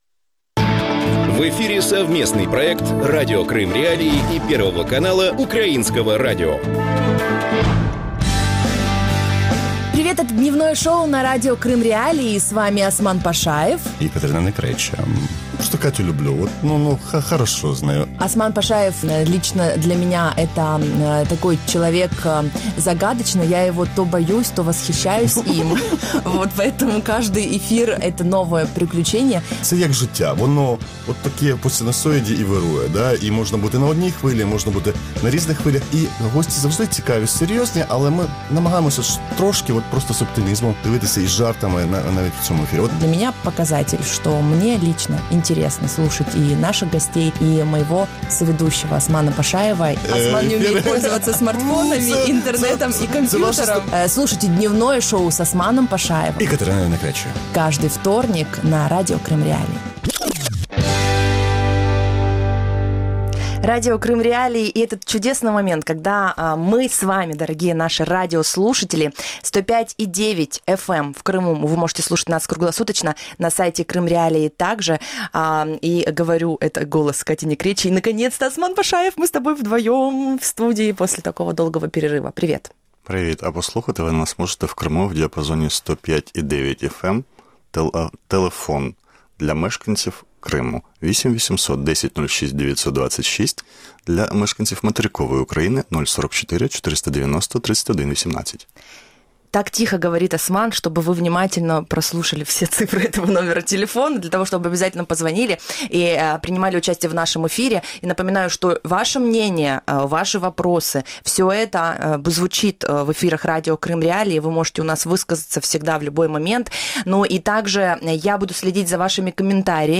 И как можно привлечь внимание к ситуации в Крыму во время Чемпионата мира по футболу? Об этом – в «Дневном шоу» в эфире Радио Крым.Реалии с 12:10 до 12:40.